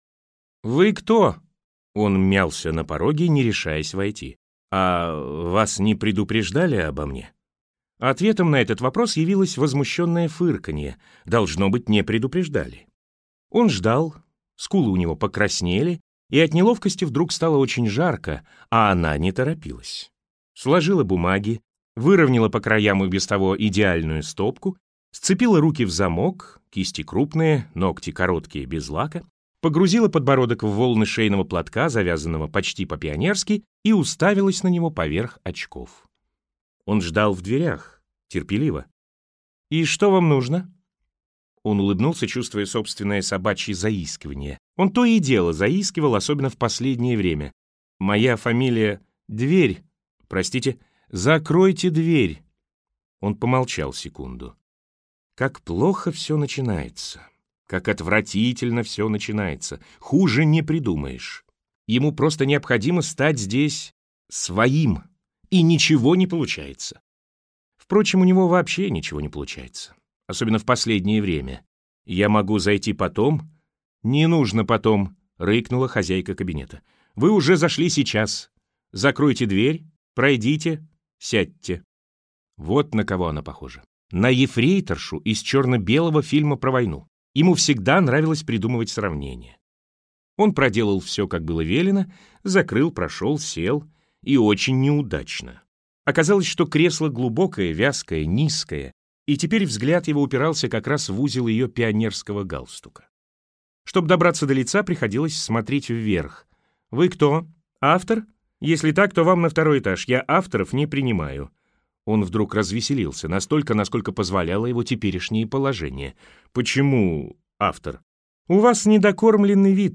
Аудиокнига С небес на землю - купить, скачать и слушать онлайн | КнигоПоиск